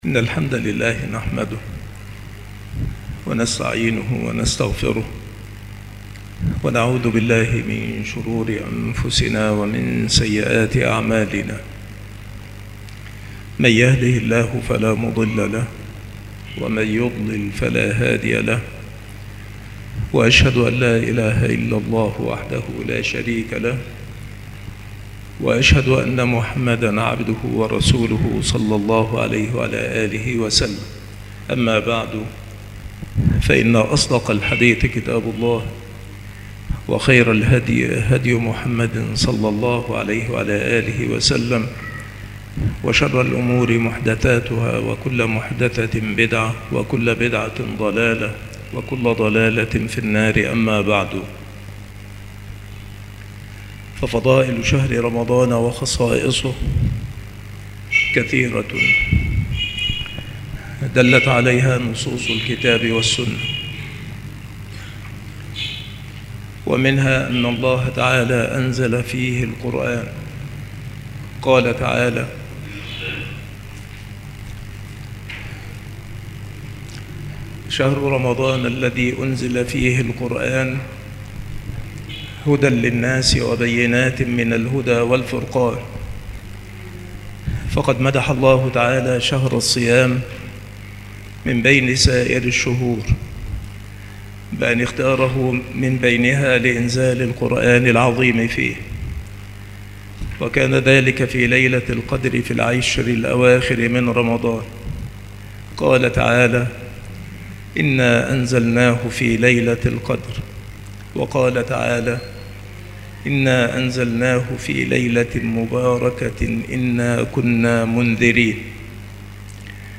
المحاضرة
مكان إلقاء هذه المحاضرة بالمسجد الشرقي بسبك الأحد - أشمون - محافظة المنوفية - مصر